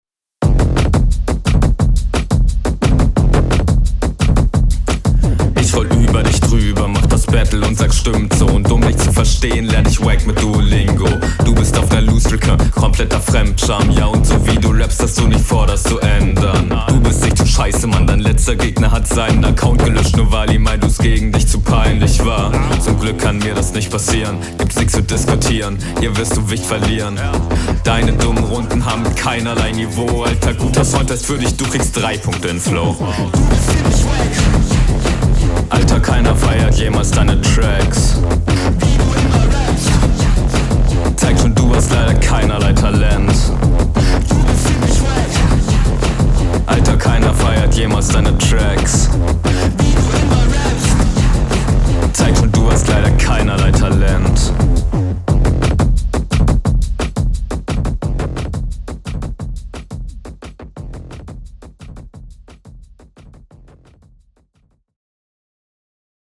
Hinrunde 1